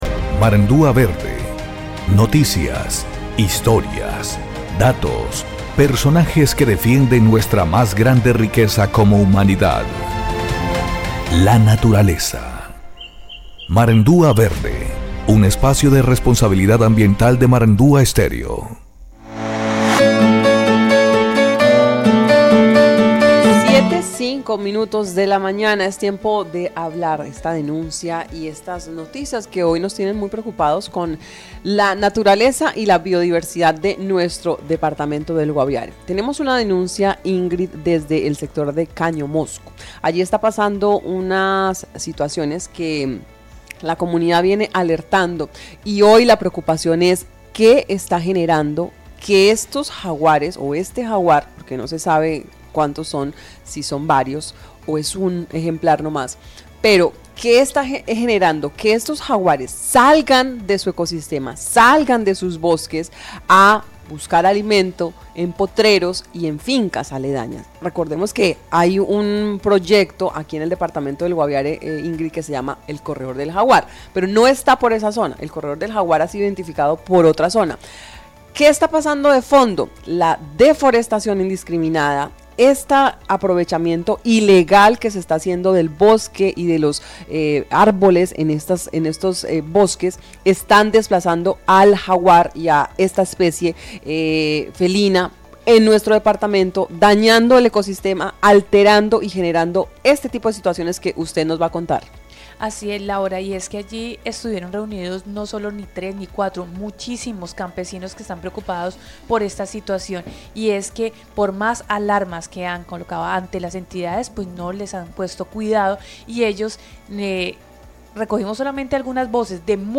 En testimonios recogidos por Marandua Noticias, los pobladores relataron cómo personas externas están ingresando al territorio para cortar madera de forma ilegal, deteriorando el ecosistema y aumentando los conflictos entre humanos y fauna silvestre.